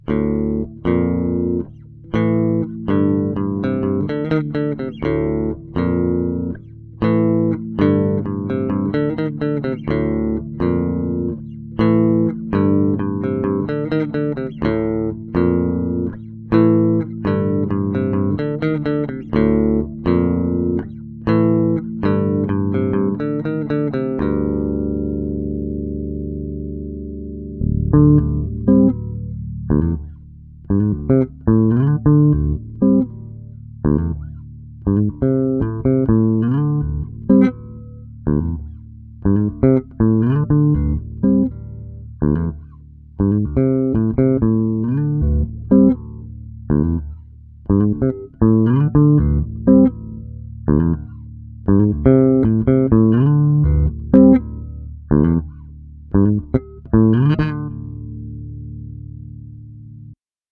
Below are just a few riffs that I recorded so I won't forget.
- Fender Jazz V mutterings.
It has passive pickups which offer noise yet no color to tone.